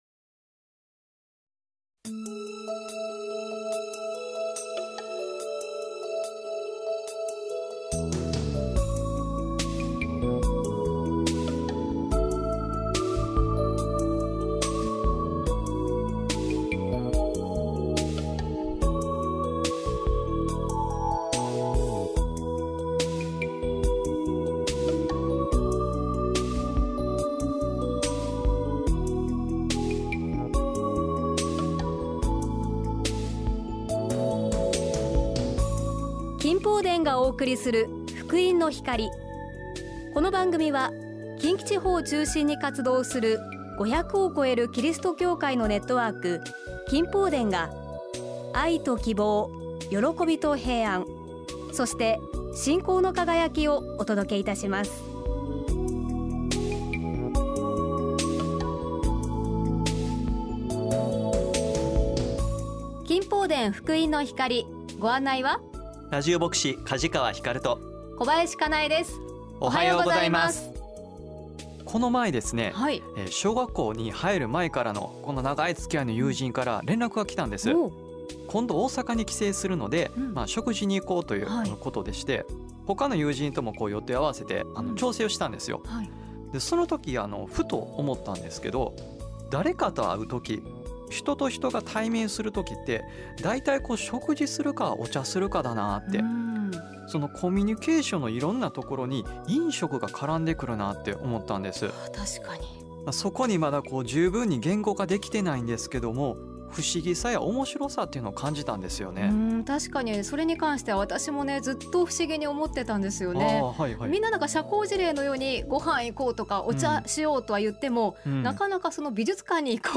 御言葉とお話
信仰体験談